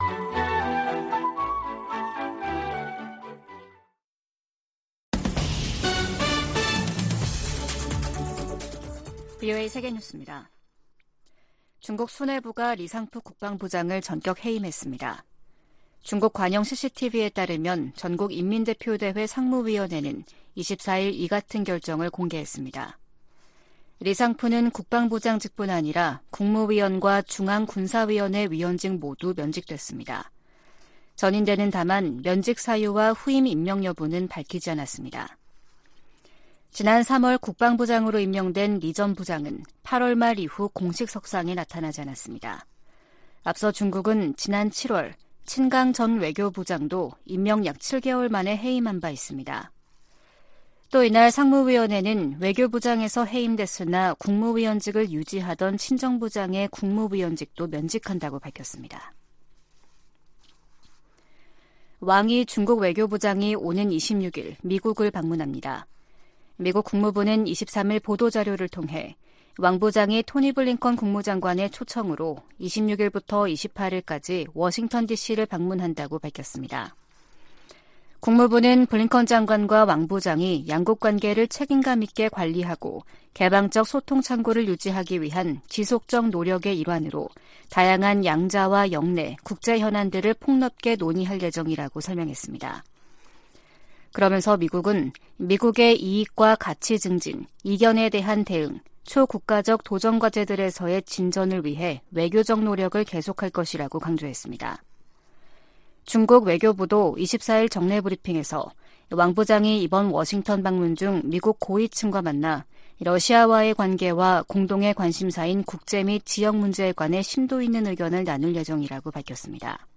VOA 한국어 아침 뉴스 프로그램 '워싱턴 뉴스 광장' 2023년 10월 25일 방송입니다. 북한 주민 4명이 소형 목선을 타고 동해 북방한계선(NLL)을 통과해 한국으로 넘어 왔습니다. 미국 정부가 북한과 러시아의 무기 거래 현장으로 지목한 북한 라진항에 또다시 대형 선박이 정박한 모습이 포착됐습니다.